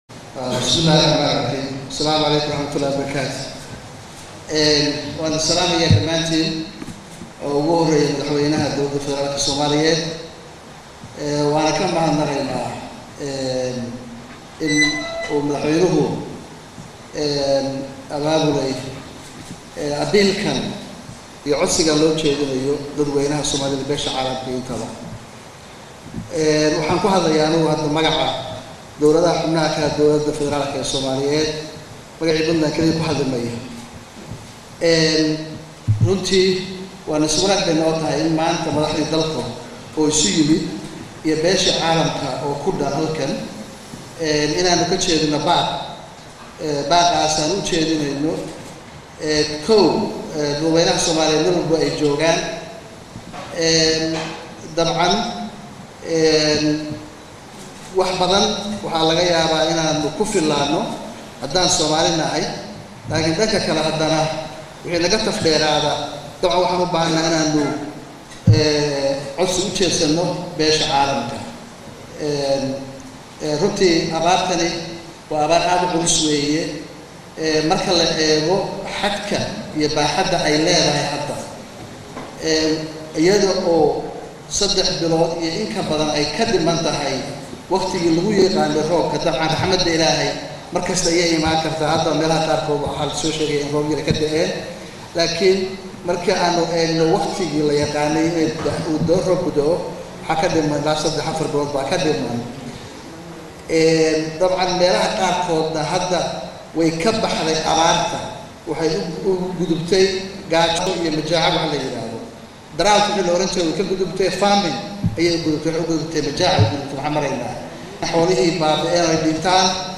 Ugu horayn Madaxweynaha Dawladda Puntland Dr. Cabdiweli Maxamed Cali Gaas oo ku hadlaya afka maamul goboleedyada dalka ayaa baaq ka jeediyey abaaraha baaxadaleh ee ka jira Soomaaliya waxaana uu ku dheeraday madaxweynuhu muhiimada ay leedahay in ladareemo dhibaatada  haysata dadka Soomaaliyeed ee baarahu saameeyeen,sidoo kalena waxa uu baaq udiray ummada Soomaliyeed, beesha caalamka iyo cid kasta oo gacan ka gaysan karta garmadka loo fidinaayo dadka abaaruhu halakeeyeen.
Codka-Madaxweynaha.mp3